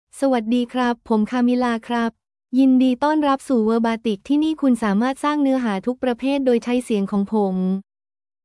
Camila — Female Thai AI voice
Camila is a female AI voice for Thai (Thailand).
Voice sample
Listen to Camila's female Thai voice.
Camila delivers clear pronunciation with authentic Thailand Thai intonation, making your content sound professionally produced.